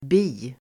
Uttal: [bi:]